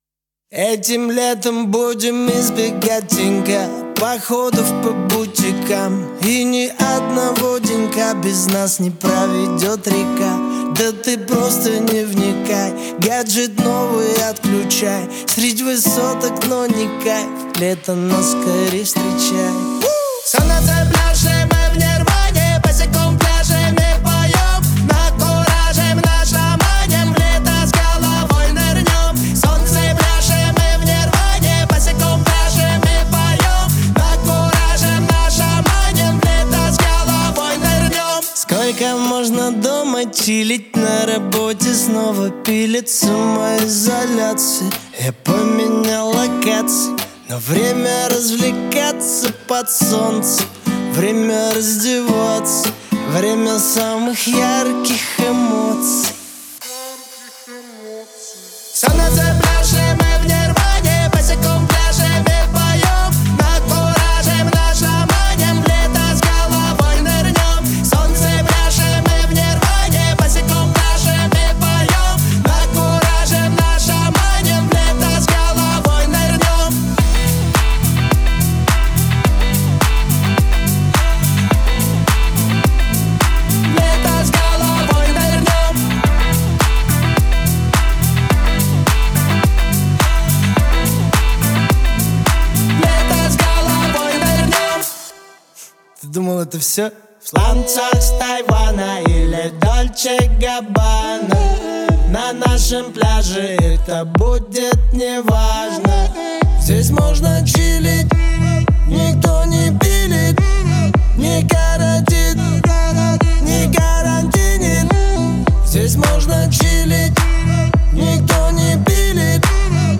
отличается харизматичным вокалом и уверенной подачей